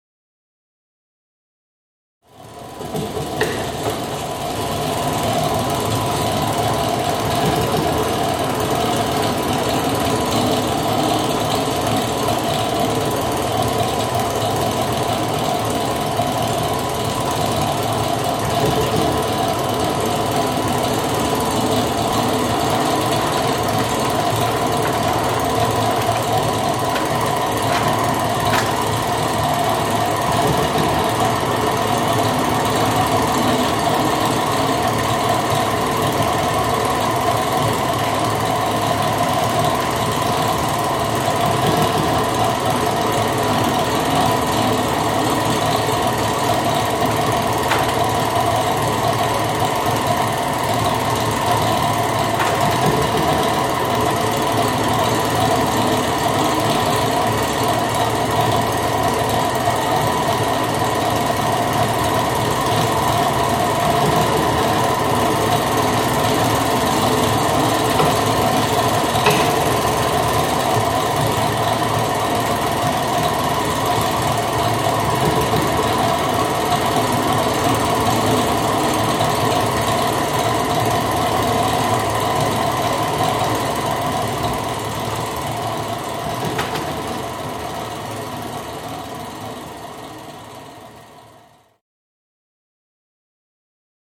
Performative Installation